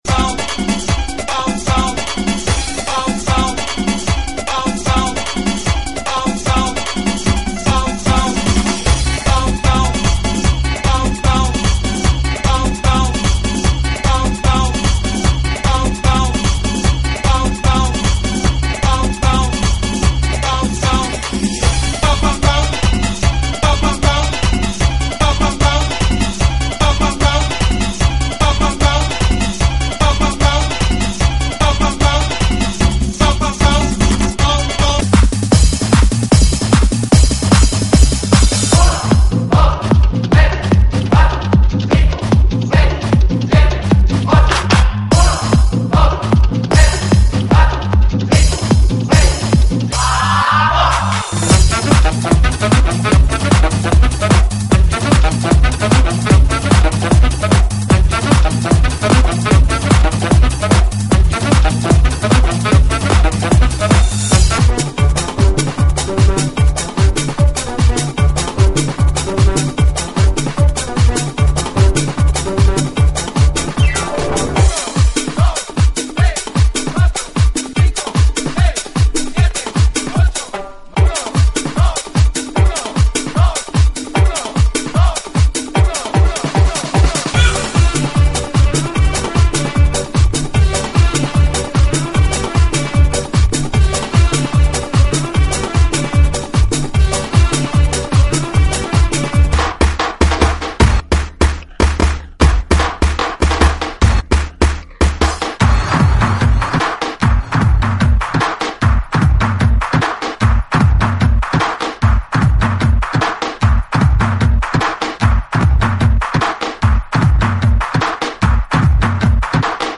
GENERO: LATINO – RADIO